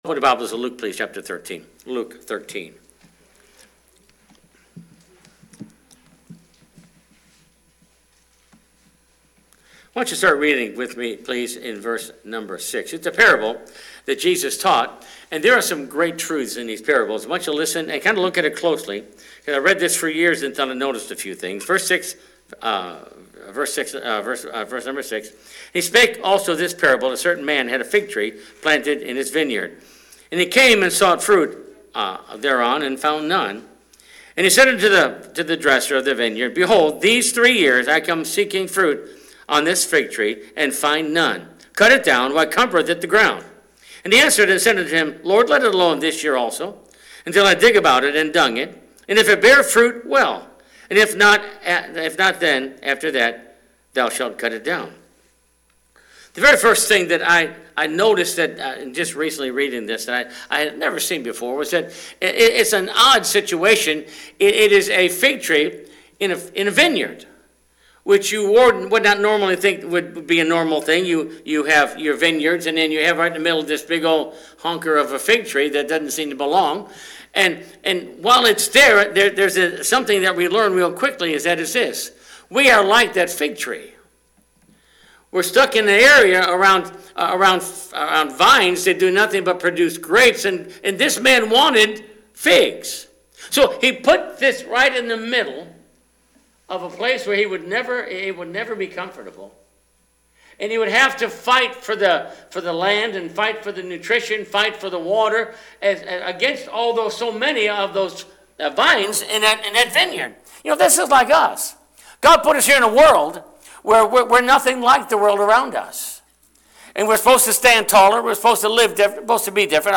Recent Sermons
From the pulpit of Bethel Baptist Church